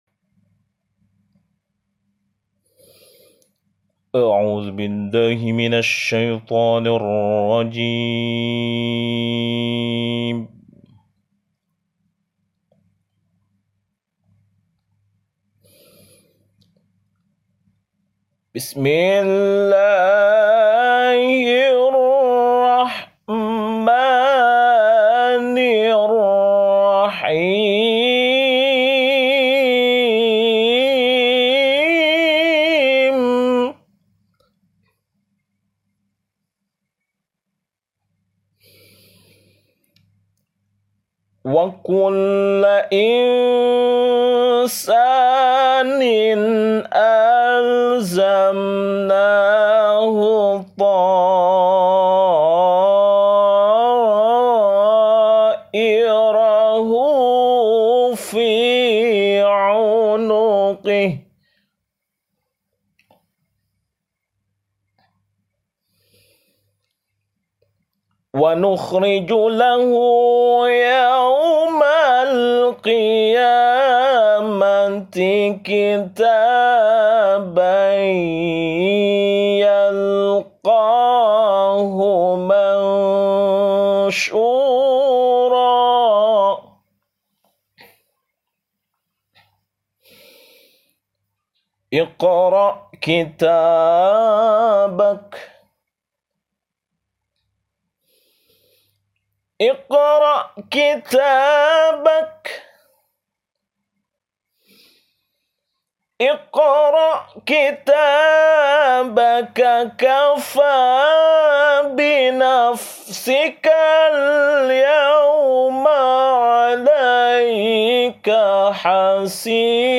Pembacaan Ayat Suci Al-Qur’an
Kalam-Illahi-Wisuda.mp3